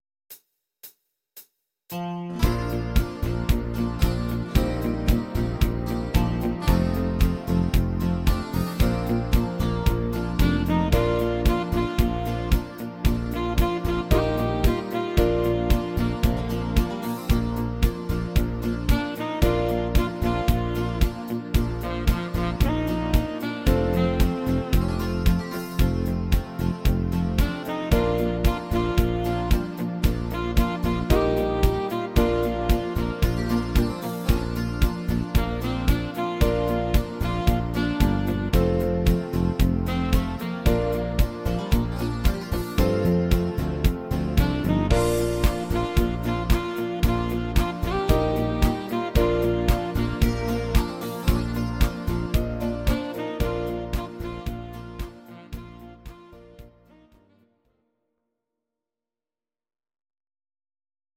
Audio Recordings based on Midi-files